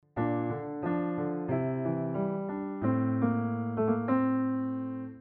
Помогите найти этюд